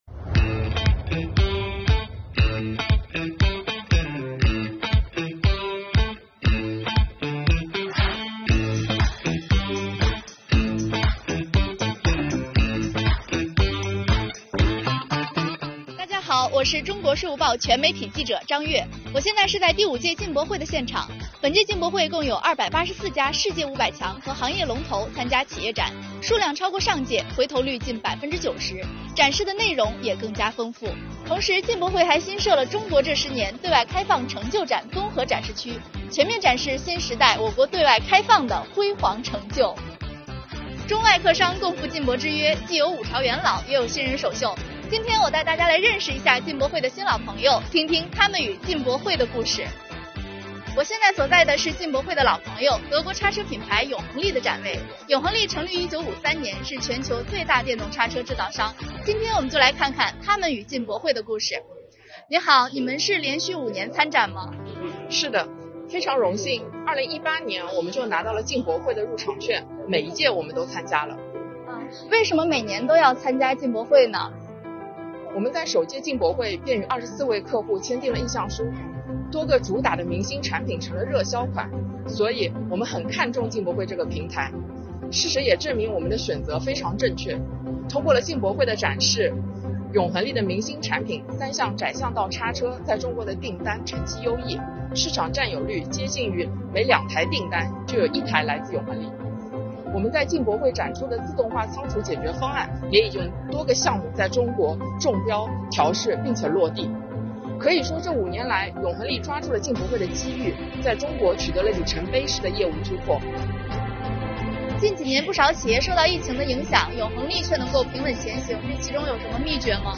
中外客商共赴“进博之约”，既有“五朝元老”，也有“新人首秀”，快来跟随镜头去听听他们与进博会的故事吧。